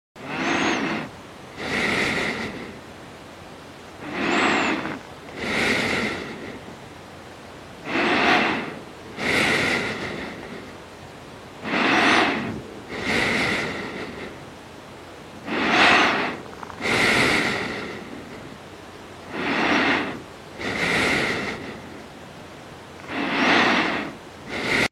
Sonneries » Sons - Effets Sonores » bruitage respiration